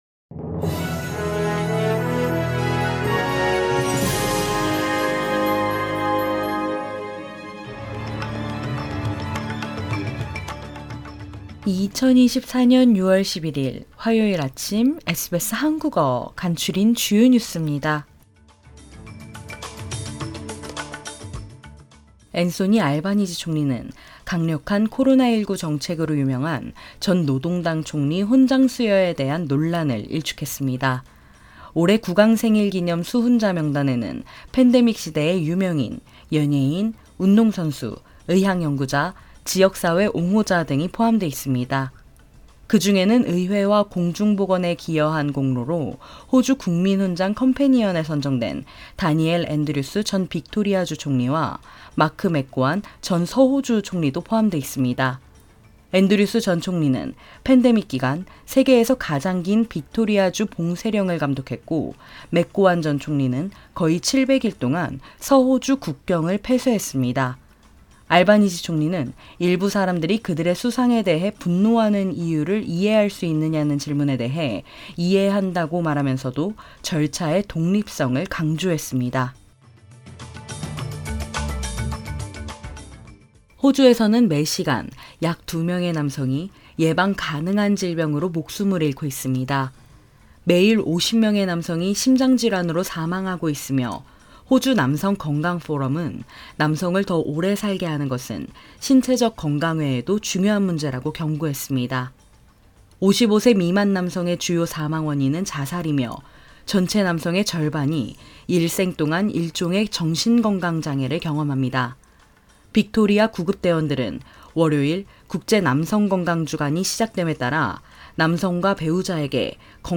SBS 한국어 아침 뉴스: 2024년 6월11일 화요일